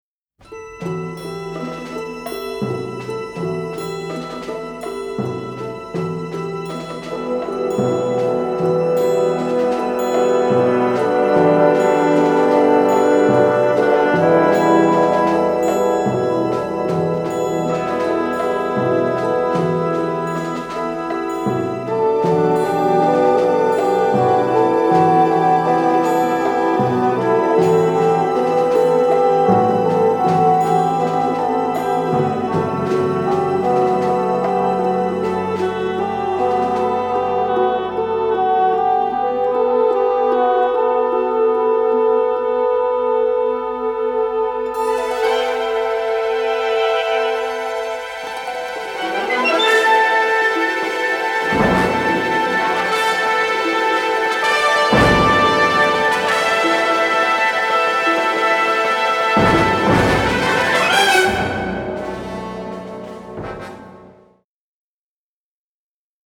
striking western score
unedited 2-track stereo masters